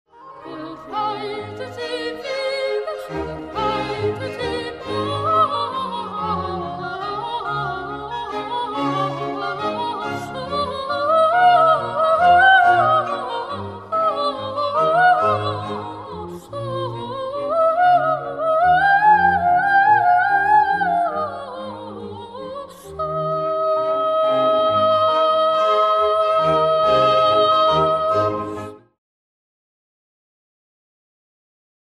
Een melisme heet dat vziw, en die komen echt niet alleen maar in het Frans
Het arme jongetje doet zo'n 25
seconde over 'Bahn' (en snakt in die tijd drie keer hoorbaar naar adem :-).
melisme.mp3